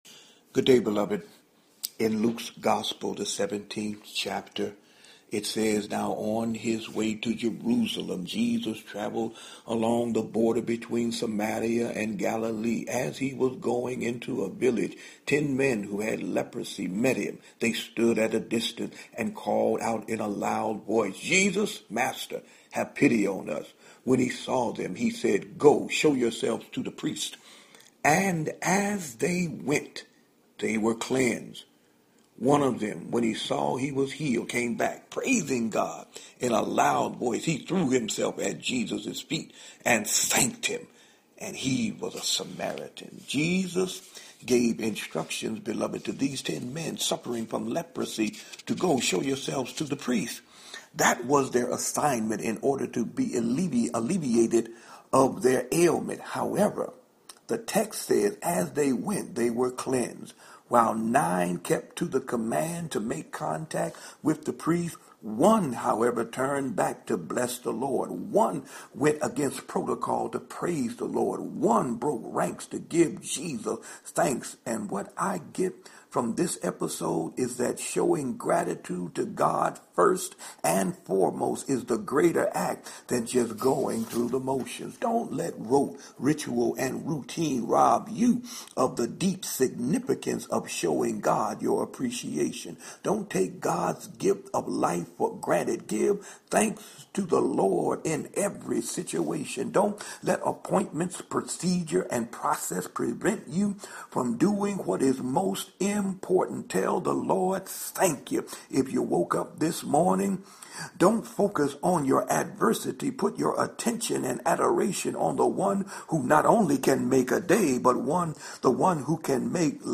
Hump Day Homily